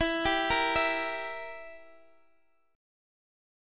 Edim chord